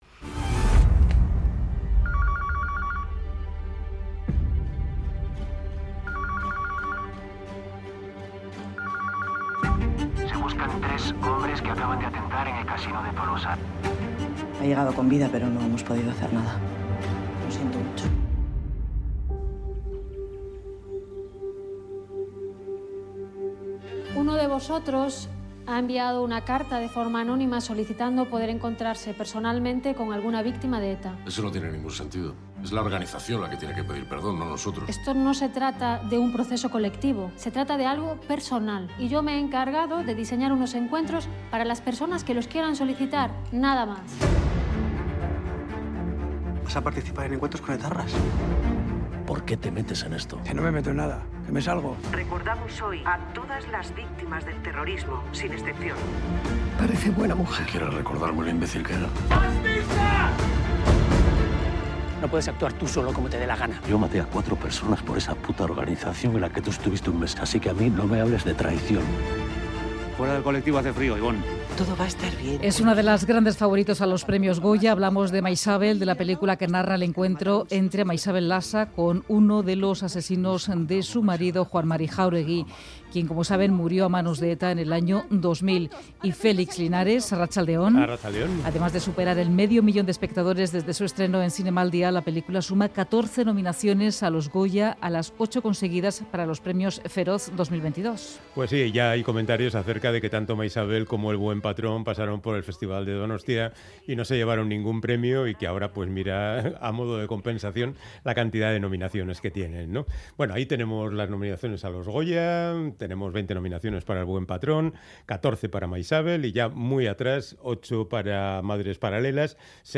Entrevista a la directora de la película 'Maixabel' tras recibir 14 nominaciones a los Premios Goya.